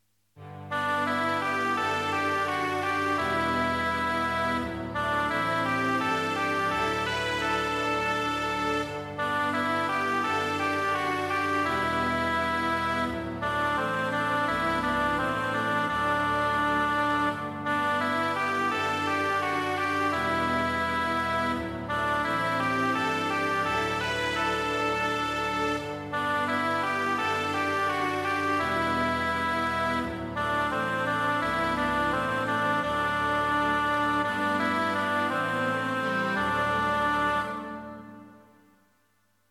1-S